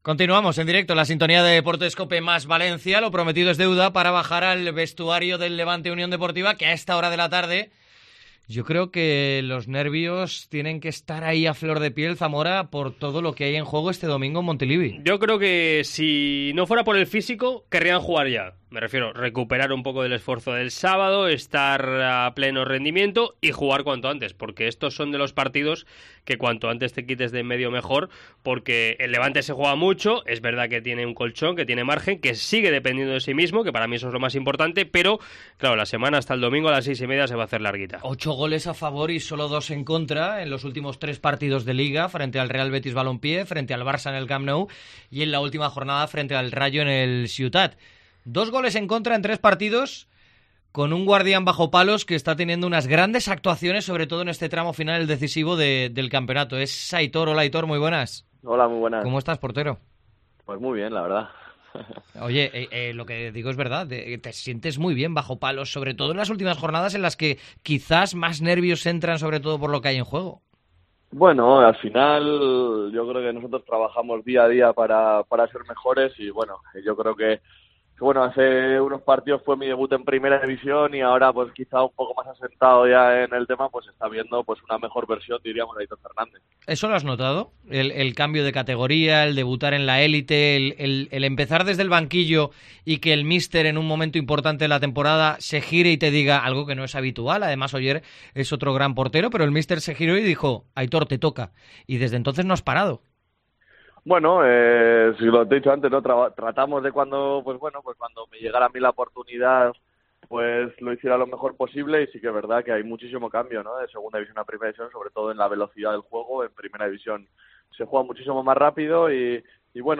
Aitor atiende a Deportes COPE Valencia en la semana decisiva de la temporada. El meta apuesta por la permanencia y pide tranquilidad.
07.05 ENTREVISTA AITOR FERNANDEZ